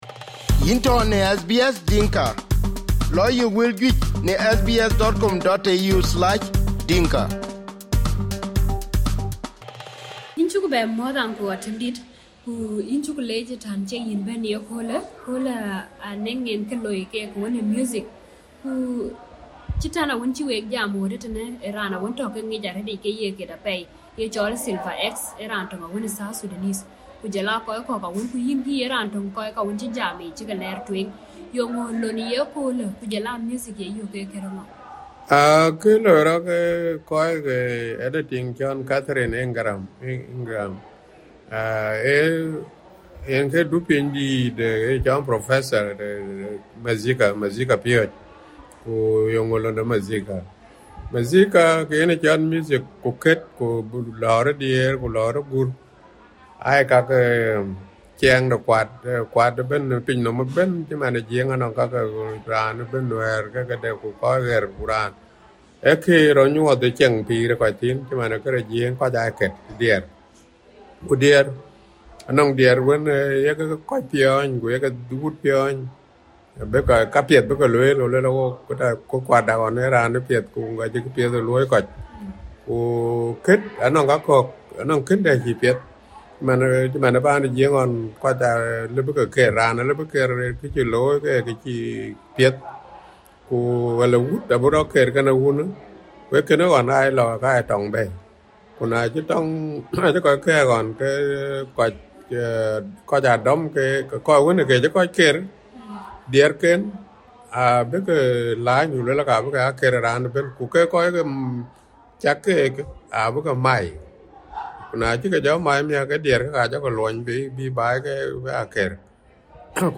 Panel discussion with prominent men from South Sudanese Australian community